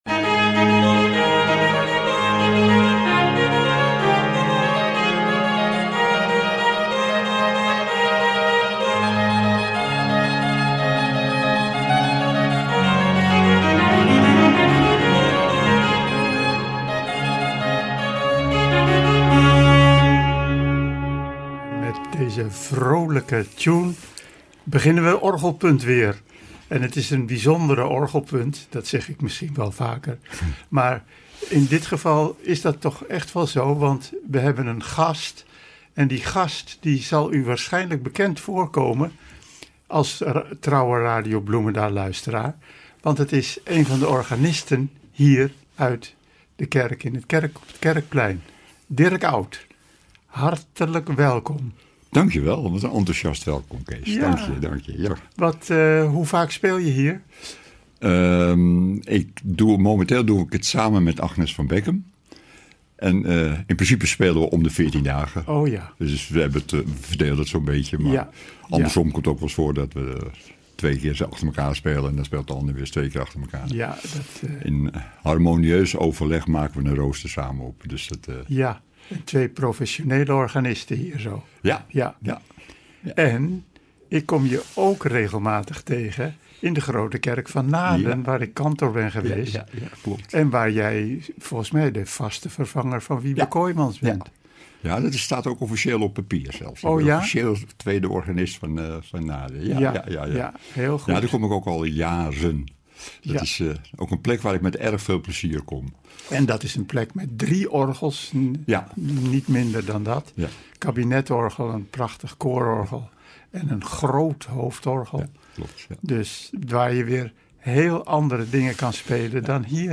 orgelmuziek